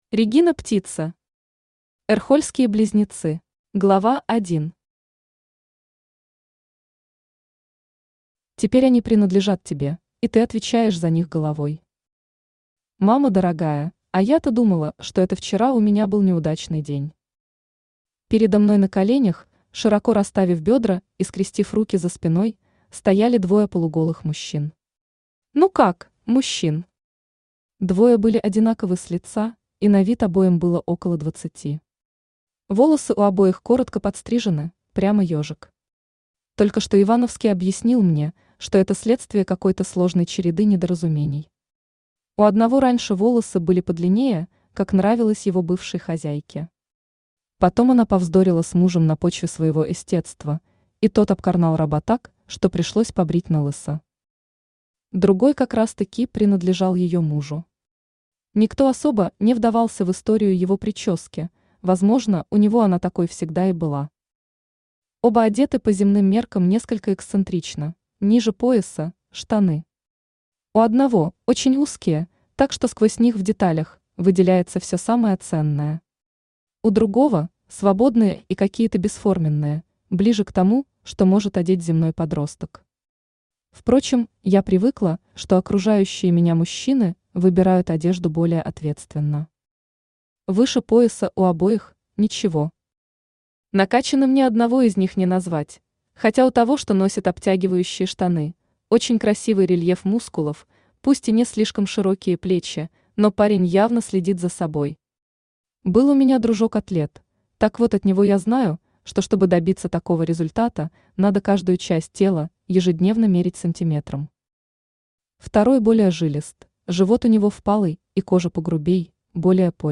Aудиокнига Эрхольские близнецы Автор Регина Птица Читает аудиокнигу Авточтец ЛитРес.